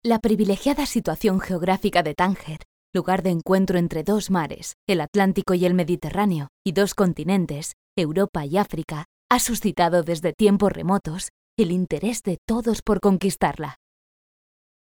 Locutora, home studio, actriz
Sprechprobe: eLearning (Muttersprache):
Voice over, home studio, actress